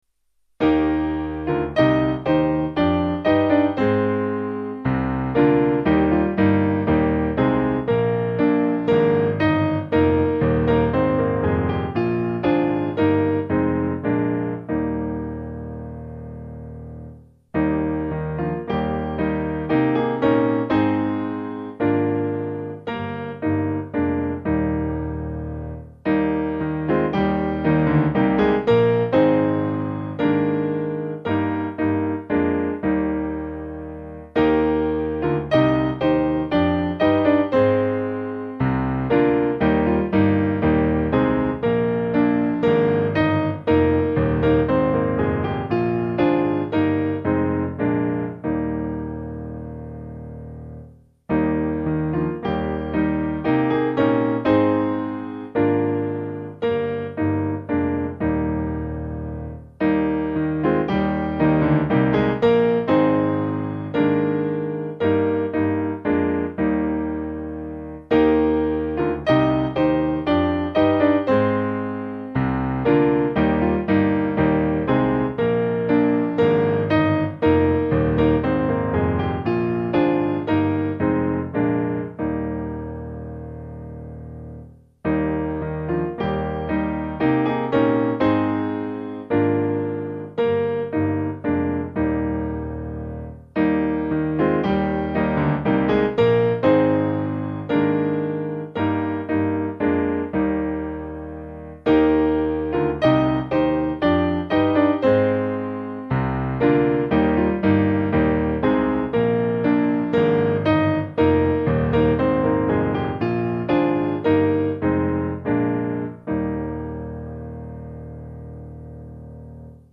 Information about the hymn tune MONKS GATE.